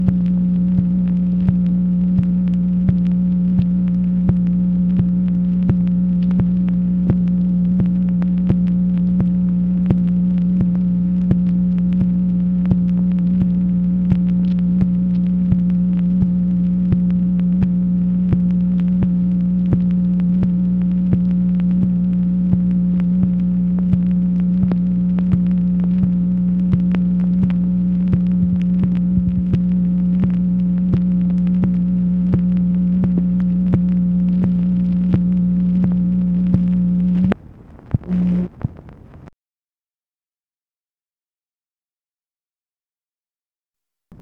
MACHINE NOISE, March 24, 1966
Secret White House Tapes | Lyndon B. Johnson Presidency